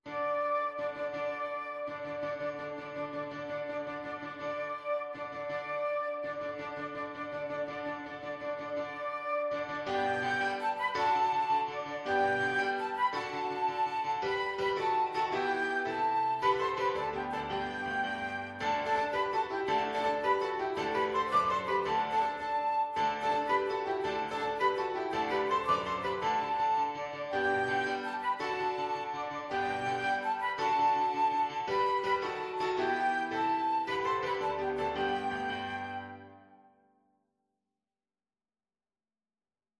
Flute
G major (Sounding Pitch) (View more G major Music for Flute )
With energy .=c.110
6/8 (View more 6/8 Music)
D6-D7
Classical (View more Classical Flute Music)